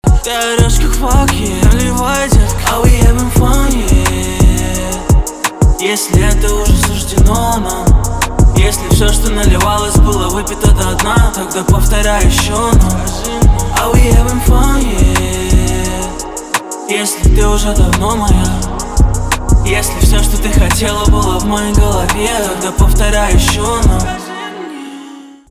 русский рэп
битовые , басы